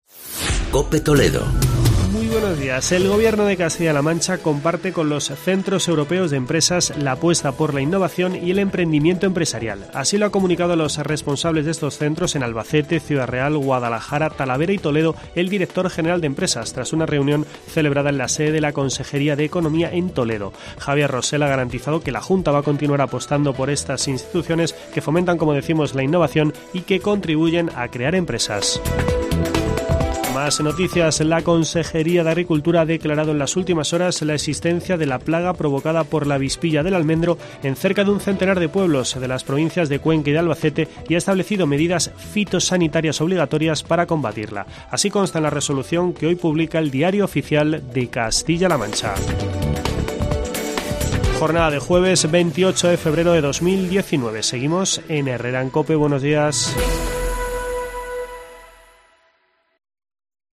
Boletín informativo de la Cadena COPE.